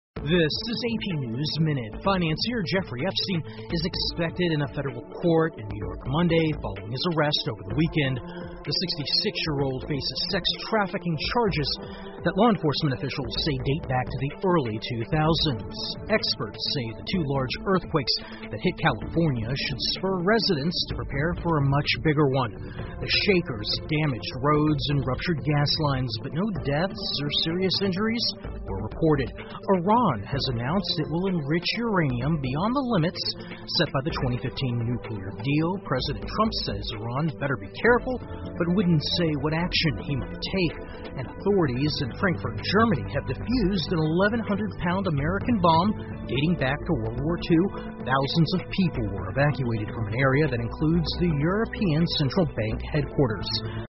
美联社新闻一分钟 AP 德国发现二战期间美国炸弹 听力文件下载—在线英语听力室